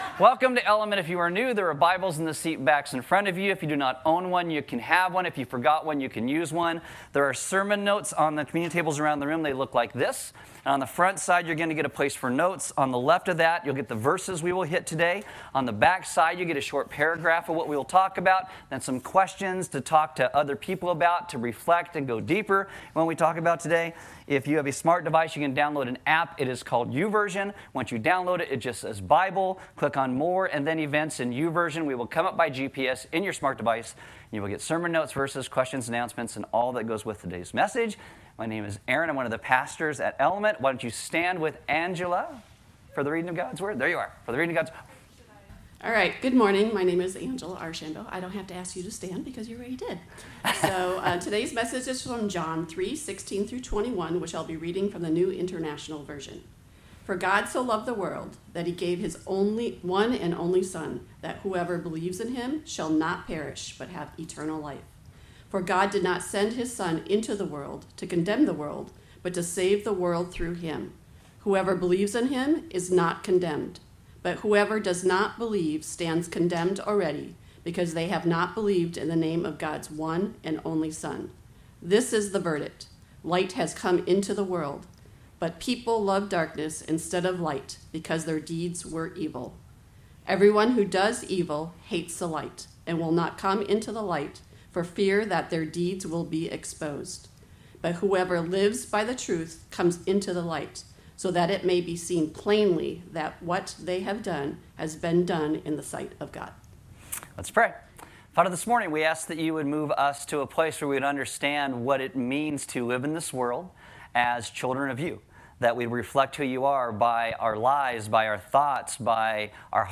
Service Audio Message The entire Bible must be kept in mind when looking at passages in different books.